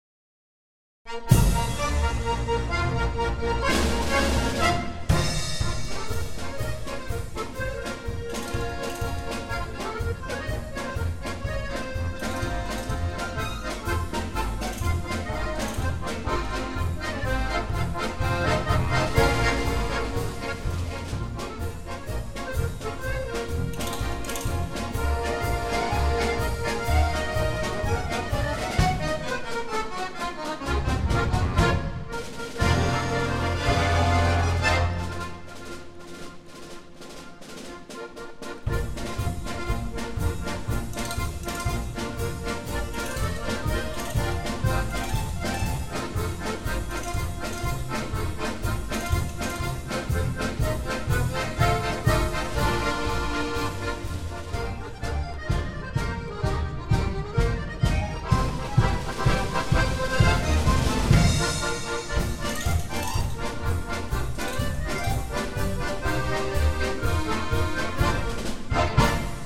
2014 – Akkordeonorchester Neustadt bei Coburg e. V.